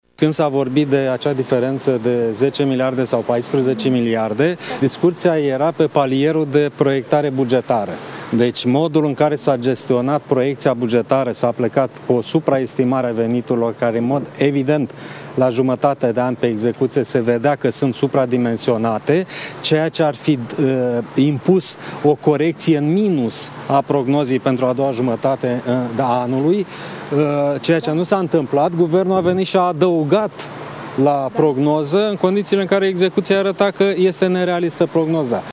Într-o primă declarație acordată presei, noul șef al ANAF a spus că până la prânz a discutat la mai multe ministere modificările pe care le va aduce prin preluarea conducerii fiscului. De asemenea Bogdan Stan a făcut referire la deficitul bugetar creat prin încasările mai mici ale ANAF față de cele estimate la rectificarea din luna noiembrie.